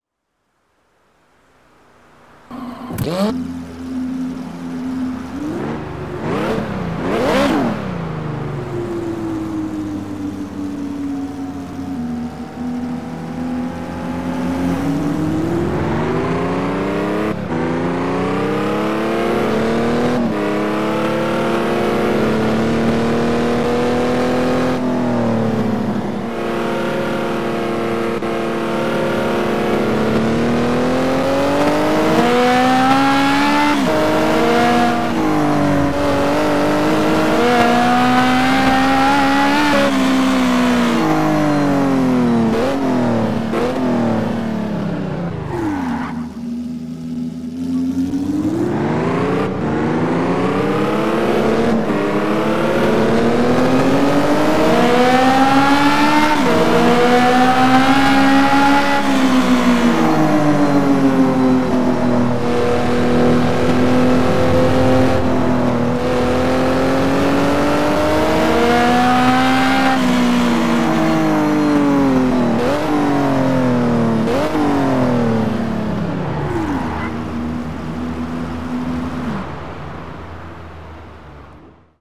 - Ferrari F430